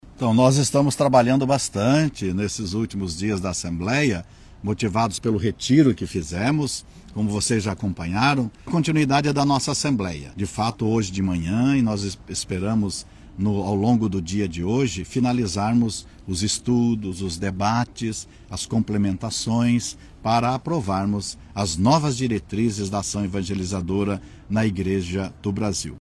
SONORA-01-DOM-MARIO.mp3